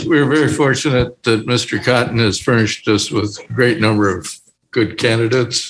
County Commission Chair Mike Quinn:
mike-quinn.mp3